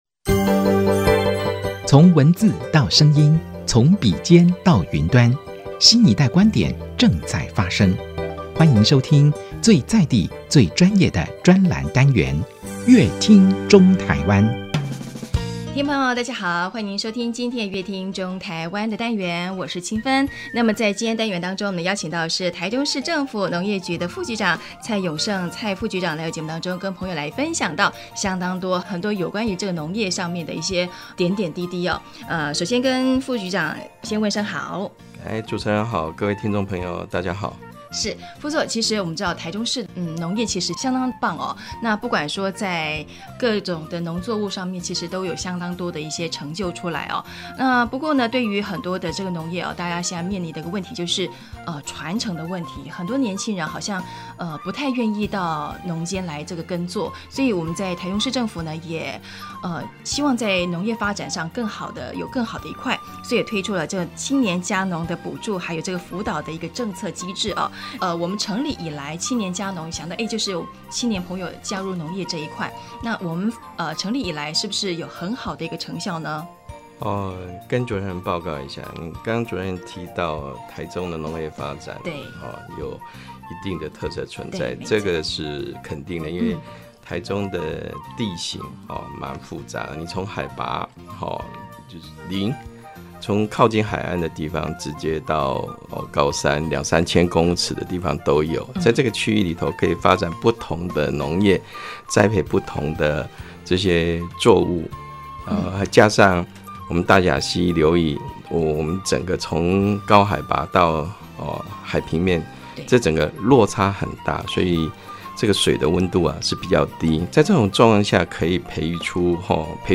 本集來賓：臺中市政府農業局蔡勇勝副局長 本集主題：為農業傳承紮根 邀集學生中青年加農